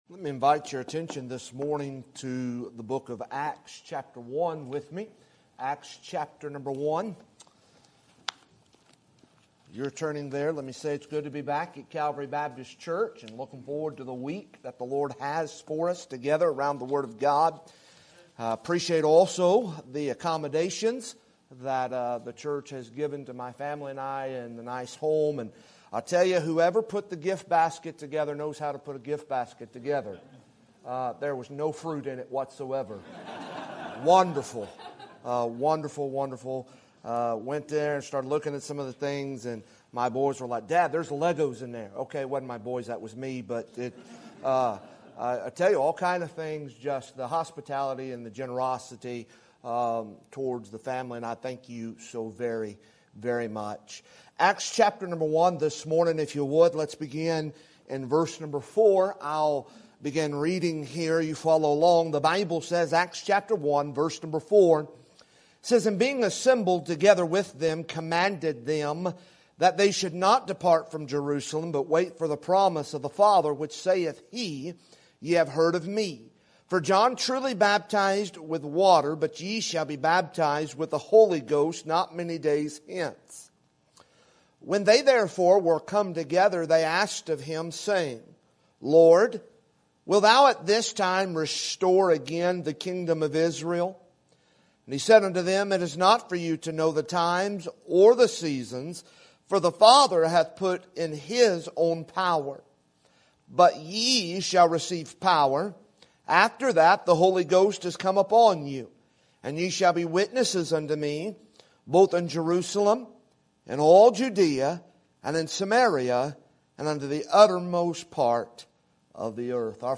Sermon Date
Sermon Topic: Missions Conference Sermon Type: Special Sermon Audio: Sermon download: Download (20.69 MB) Sermon Tags: Acts Missions Personal Empower